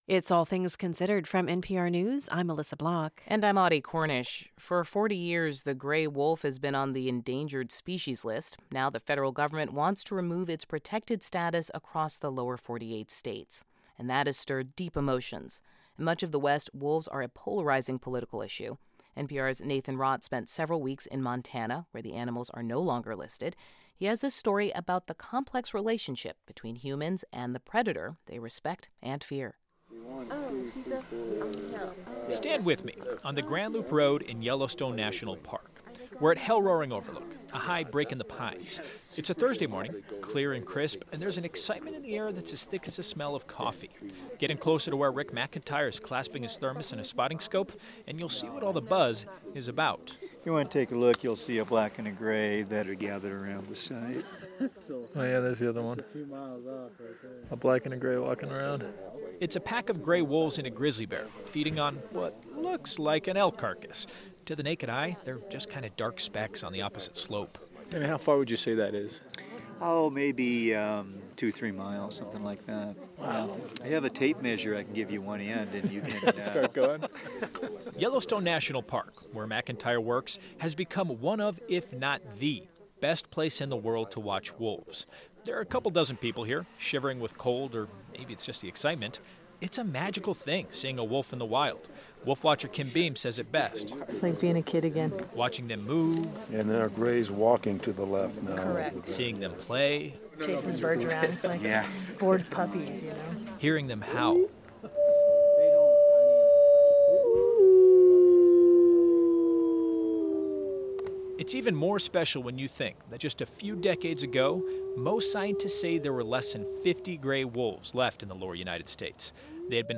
Listen to the full story from All Things Considered